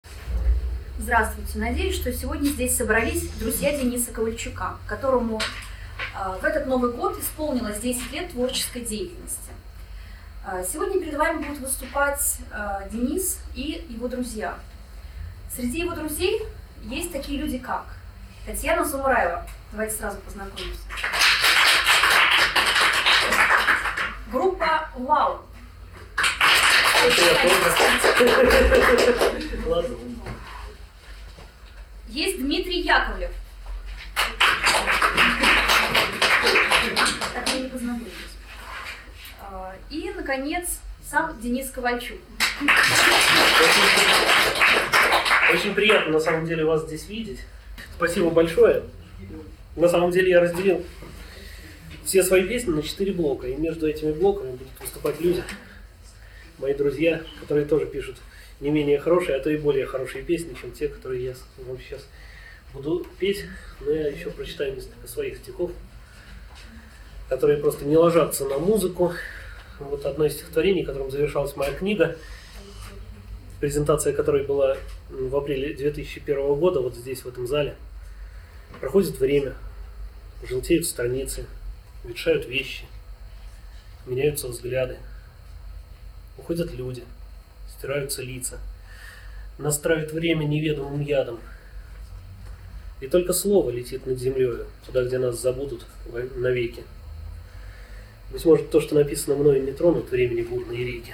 концерт в библиотеке на Юбилейной 18.01.2003